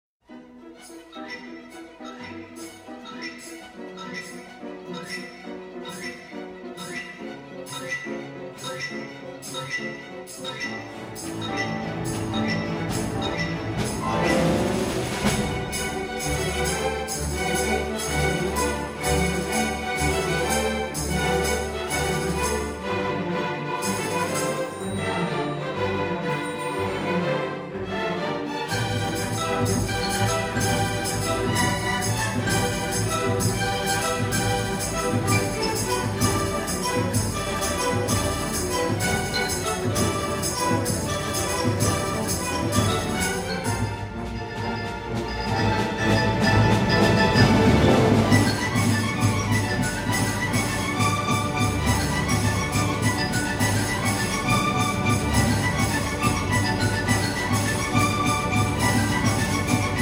perform here live in their send off concert at home